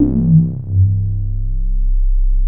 JUP 8 C2 6.wav